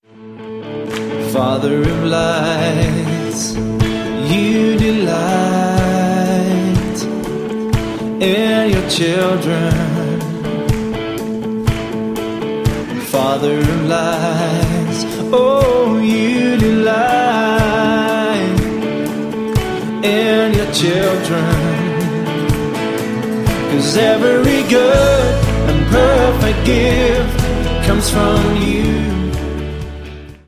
Diese Live-CD entfacht ein kreatives
• Sachgebiet: Praise & Worship